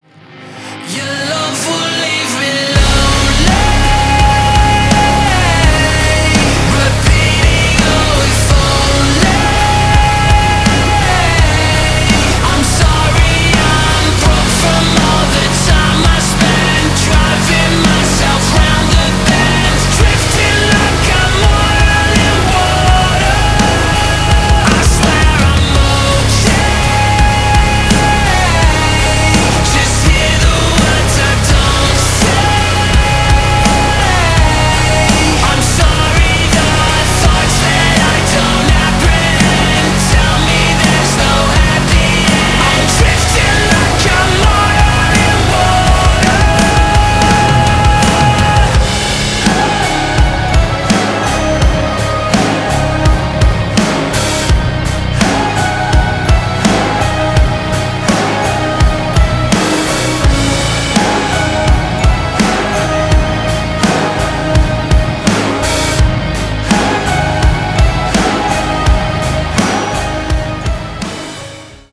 • Качество: 320, Stereo
мощные
Metalcore
Alternative Metal